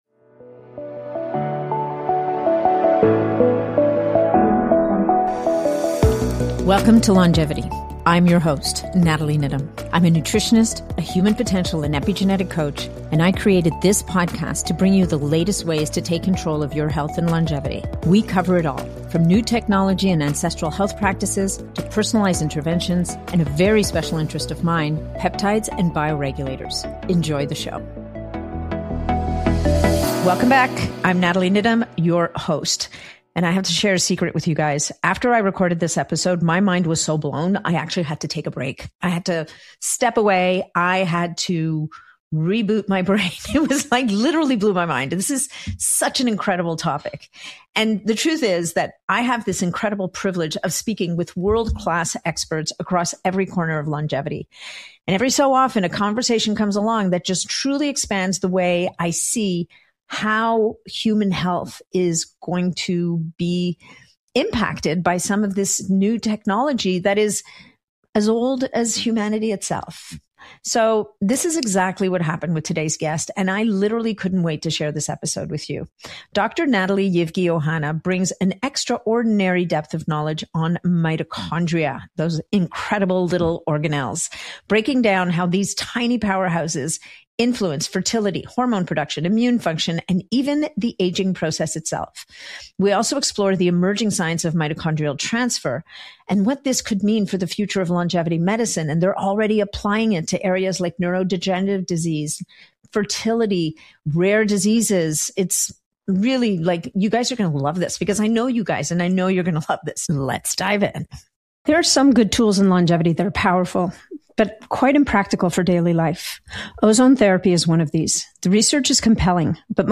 Welcome to the Longevity Podcast and today's focus on mitochondria